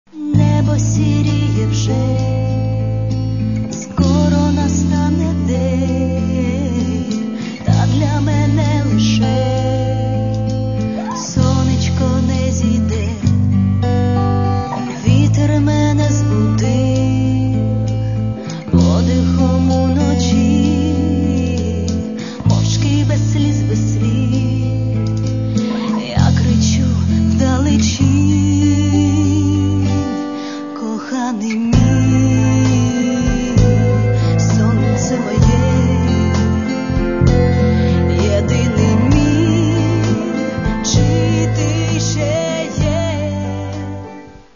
Catalogue -> Rock & Alternative -> Folk Rock